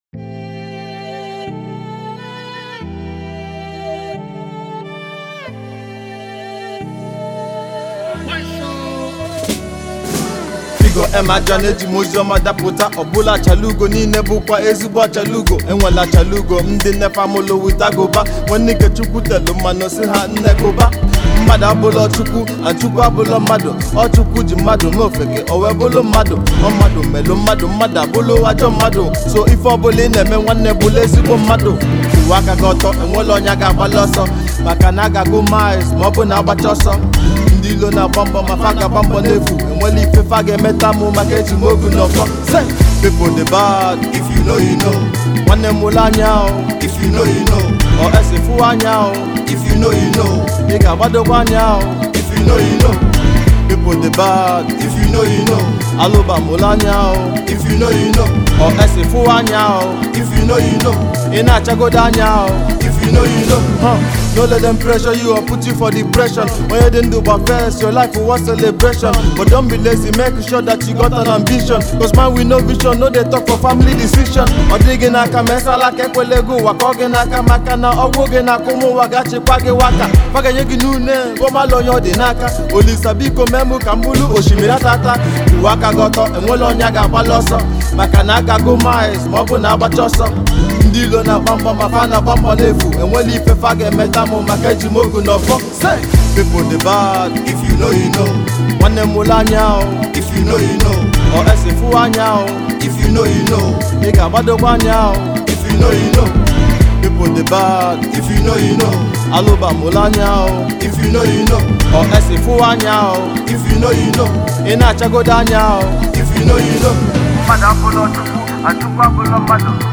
raw voice